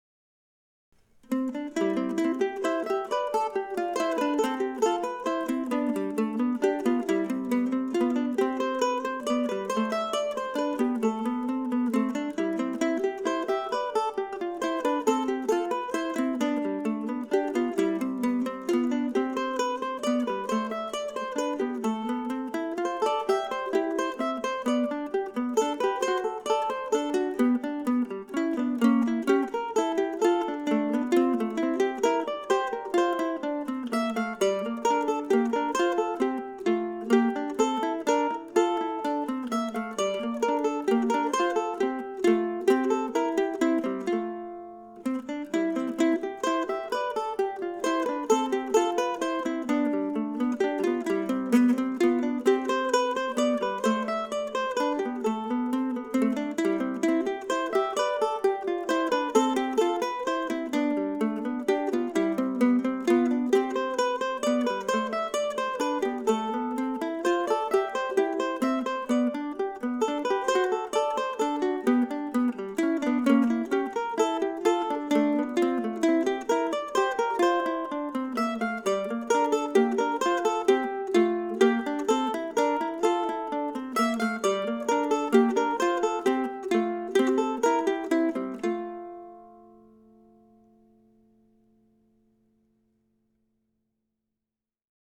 Goslings Afloat (duo version) (
Goslingsduo.mp3